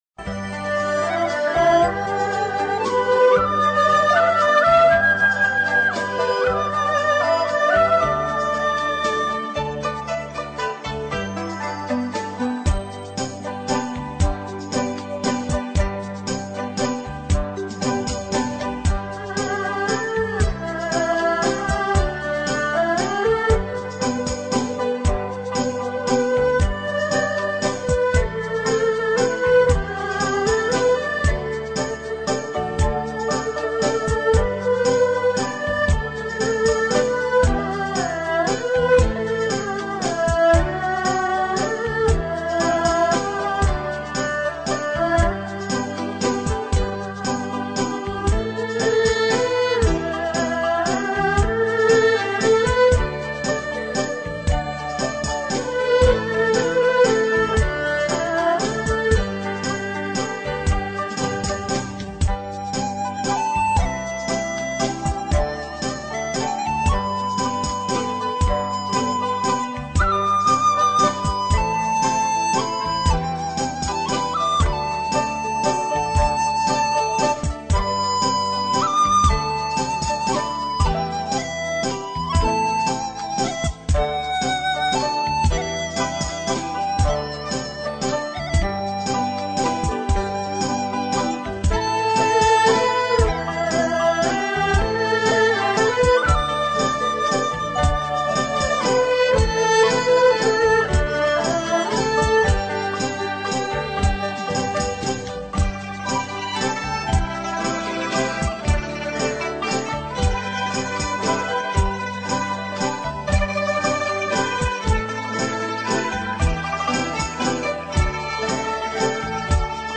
三步舞曲